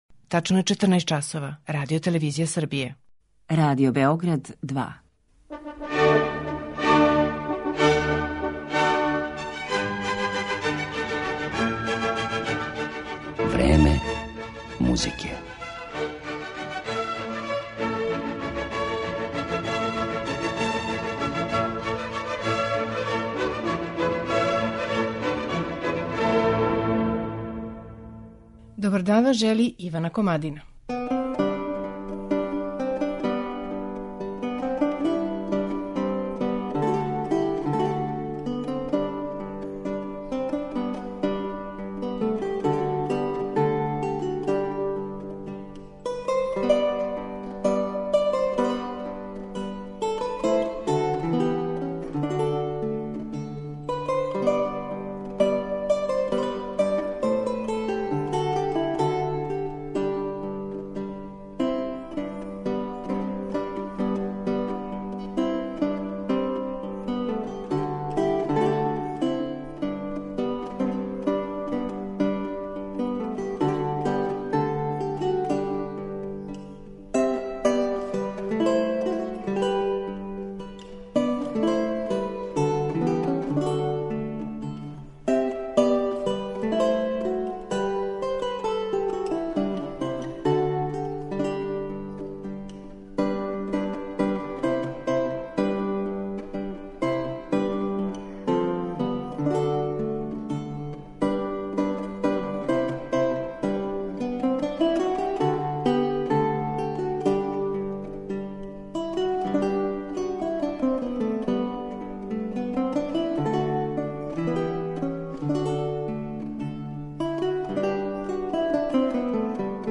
Лаутиста Пол О’Дет
У данашњем Времену музике, које смо посветили О'Дету, чућете његова тумачења дела ренесансних и барокних мајстора, попут Ђоана Амброзија Далце, Франческа да Милана, Јохана Себастијана Баха, Жан-Батисте Бесара, Сантјага де Мурсије...